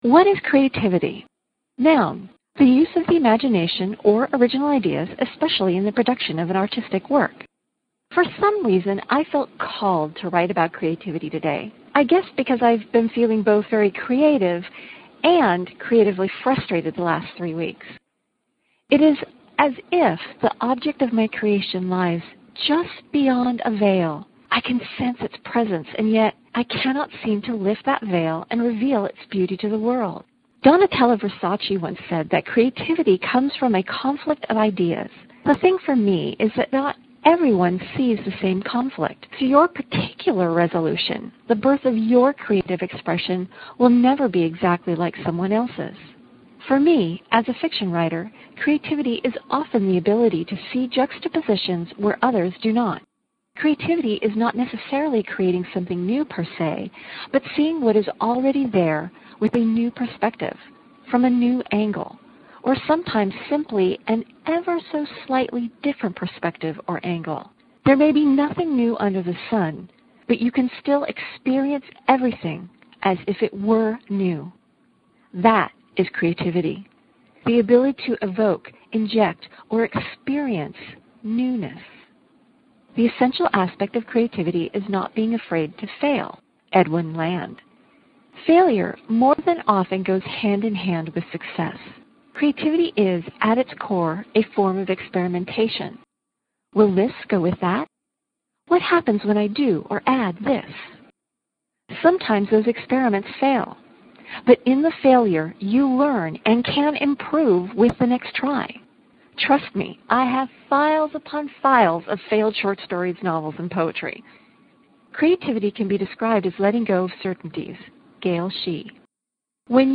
You can read this post or follow along as I read it.